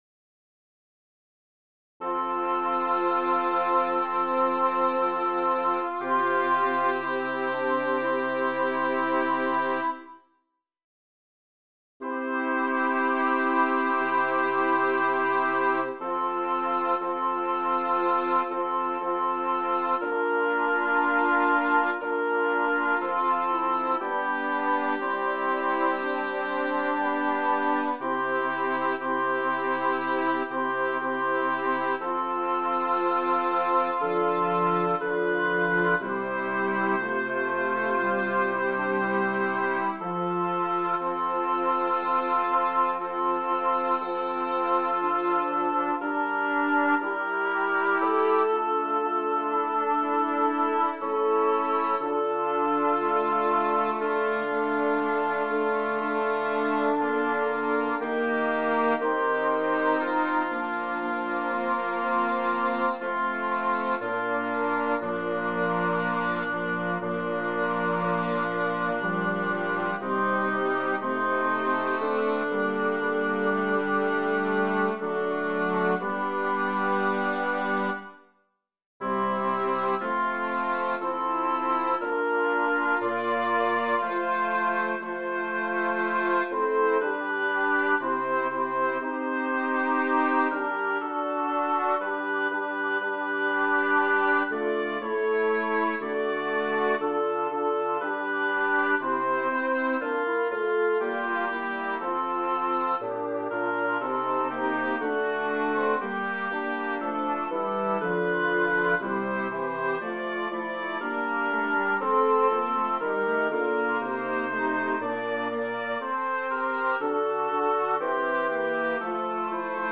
Title: Also werden die letzten die ersten Composer: Melchior Franck Lyricist: Matthew 20, 16create page Number of voices: 4vv Voicing: SATB Genre: Sacred, Motet